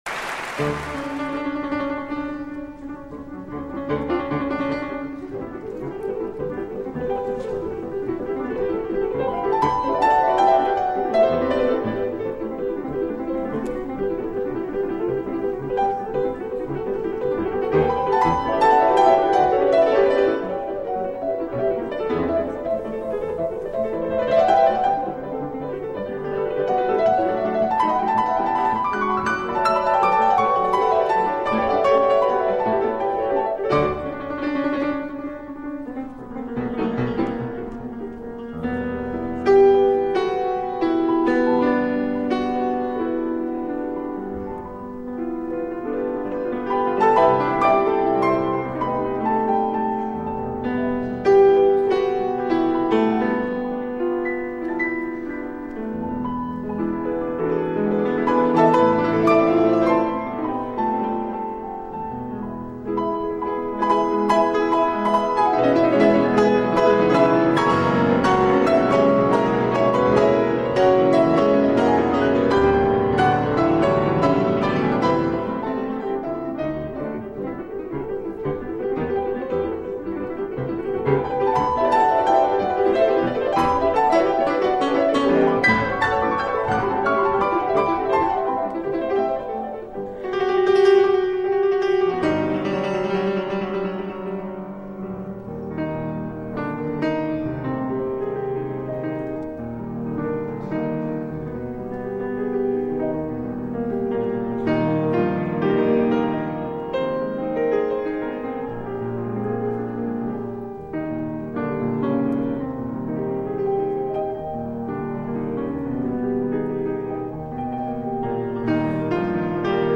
ara només a quatre mans